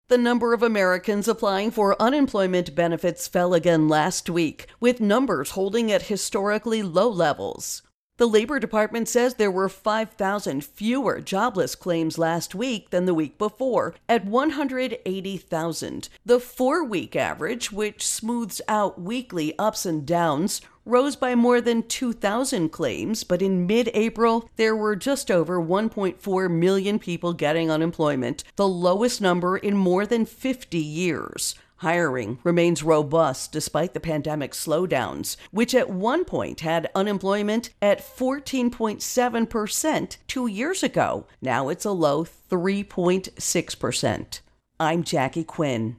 Unemployment Benefits Intro and Voicer